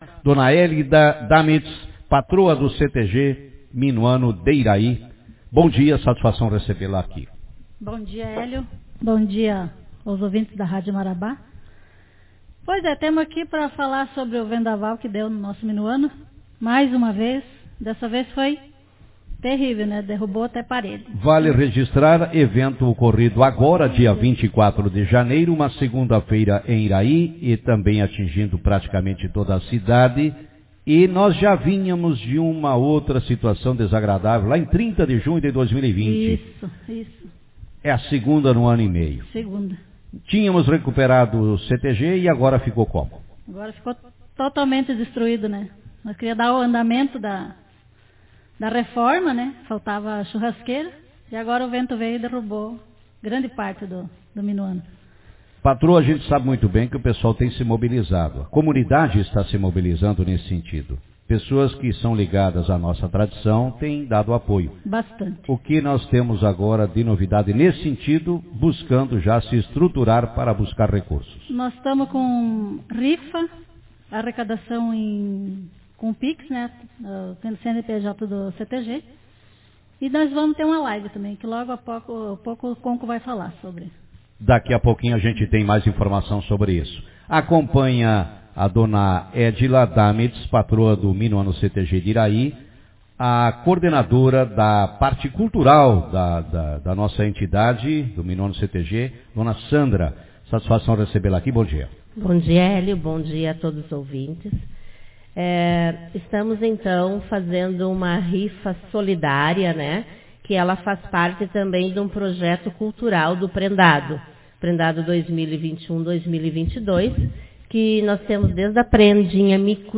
Comitiva mobiliza comunidade iraiense e regional para reconstrução do Minuano CTG Autor: Rádio Marabá 17/02/2022 Manchete Na manhã desta quinta-feira, 17, uma comitiva iraiense formada por membros da direção do Minuano CTG participou do Café com Notícias. Na ocasião, dentre os assuntos abordados, a mobilização da comunidade local e regional na reconstrução da sede do CTG destruído por um vendaval ocorrido no dia 24 de janeiro deste ano.